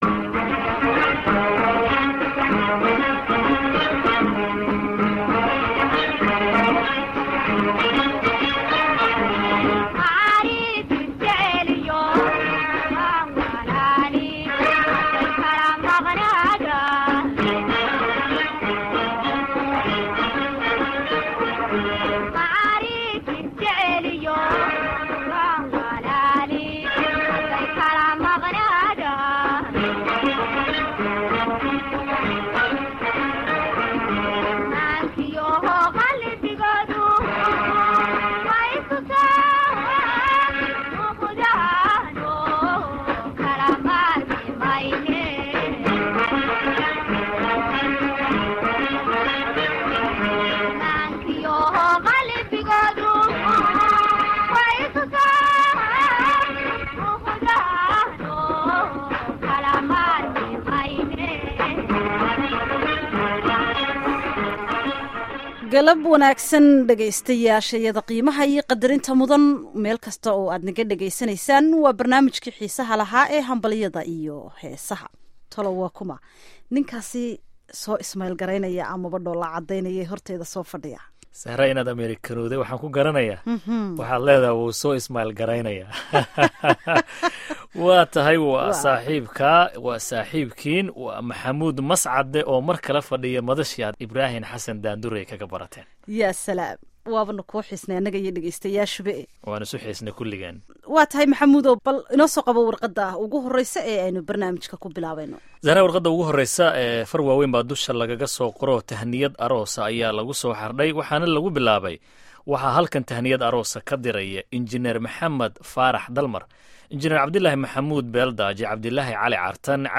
Barnaamijka Hambalyada iyo Heesaha waxaad ku maqli doontaan salaamaha ay asaxaabtu isu diraan, dhalshada, aroosyada, iyo heeso macaan oo kala duwan.